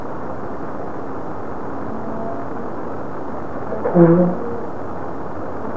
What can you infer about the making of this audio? Olympus Digital VN-1800